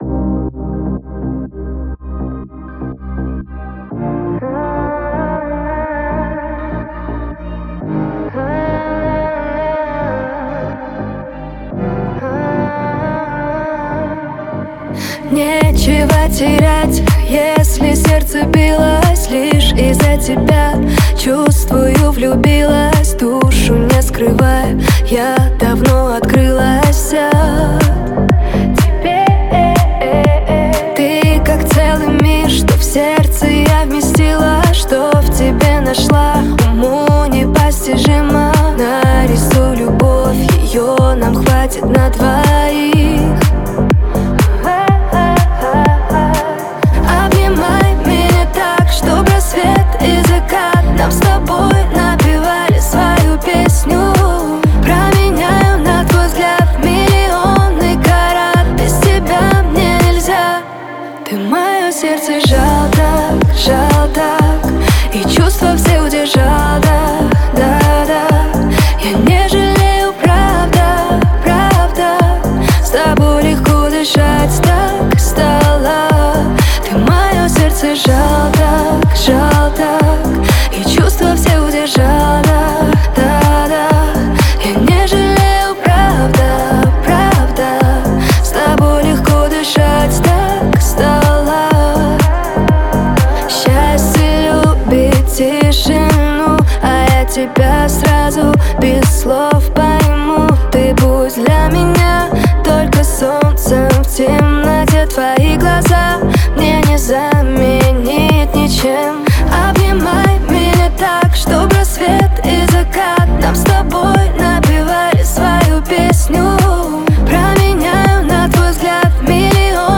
Жанр: Remix